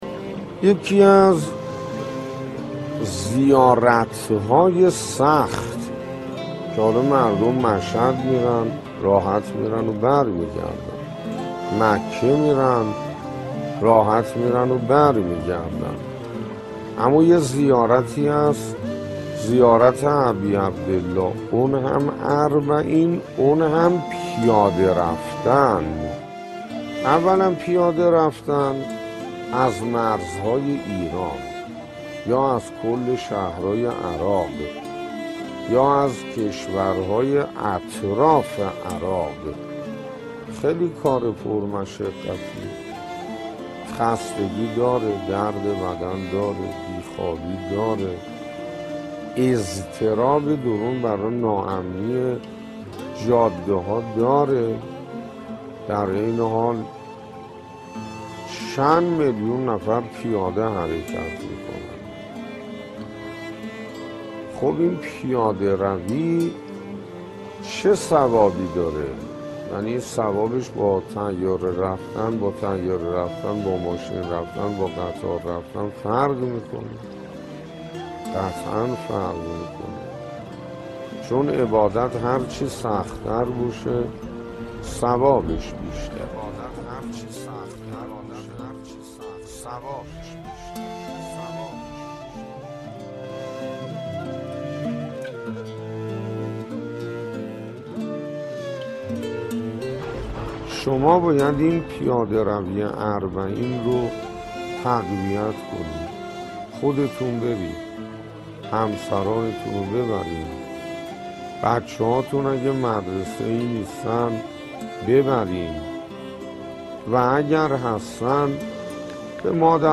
چهارمین قسمت این مجموعه، پادکست «پاداش پیاده‌روی اربعین» با کلام شیخ حسین انصاریان تقدیم مخاطبان گرامی ایکنا می‌شود.